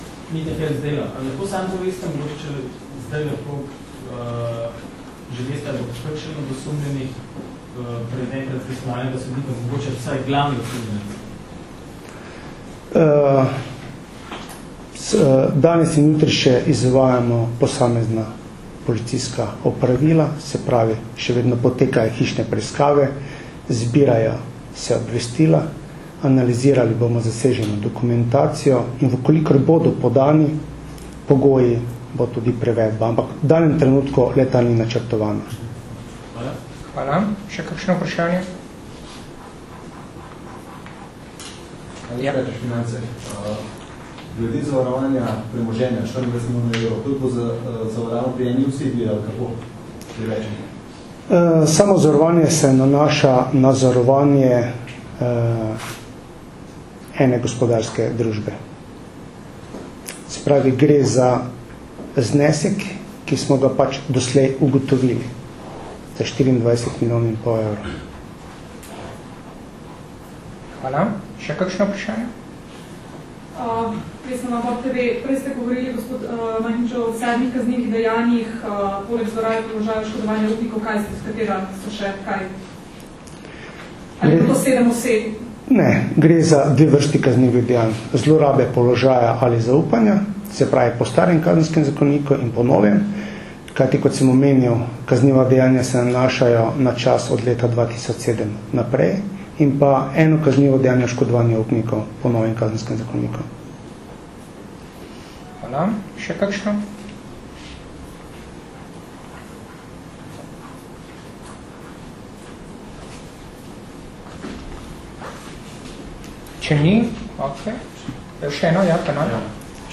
Zvočni posnetek novinarskih vprašanj in odgovorov (mp3)
vprasanja.mp3